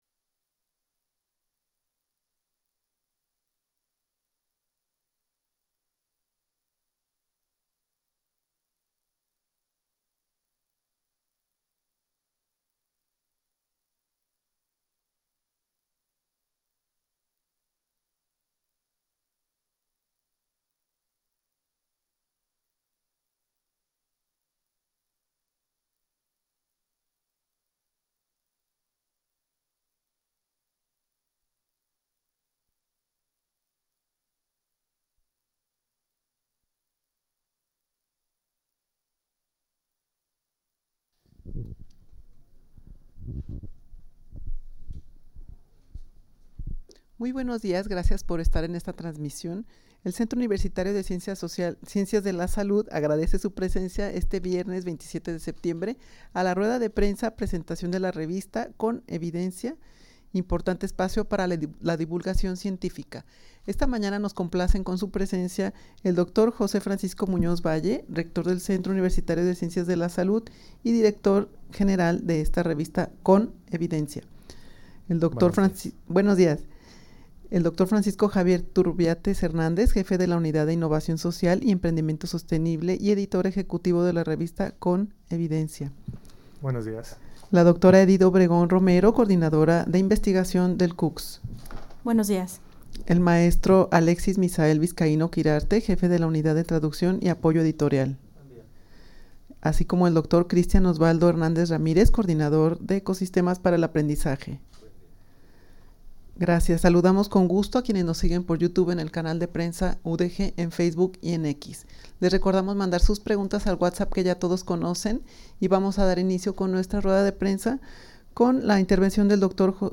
rueda-de-prensa-presentacion-de-la-revista-con-evidencia-importante-espacio-para-la-divulgacion-cientifica.mp3